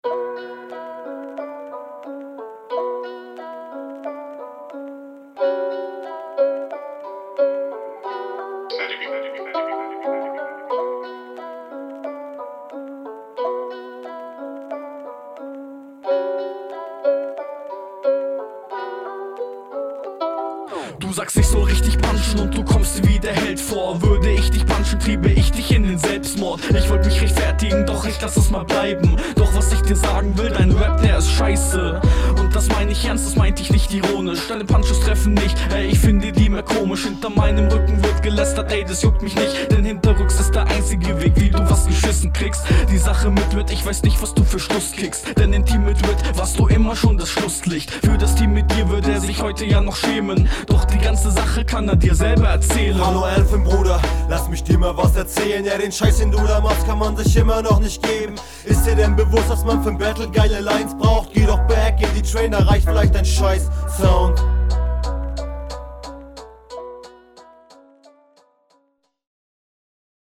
Ebenfalls komplett stabil gerappt, mehr Variation wäre besser, ist aber (auf dem Beat) nicht notwendig.